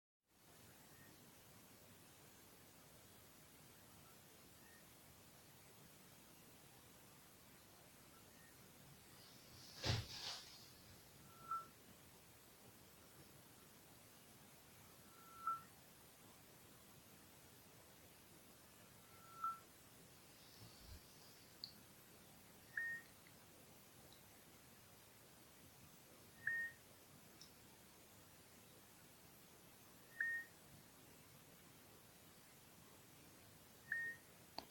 Да, и собственно сам громкоговорящий нанозвук. Желательно слушать в наущниках, записывал сотиком посреди комнаты, но он слышит заметно хуже ушей (ограничена динамика снизу), потом я его поднес к одному генератору (1.2 кГц) и другому (1.8 кГц) на расстояние несколько сантиметров, тут уже сотик справился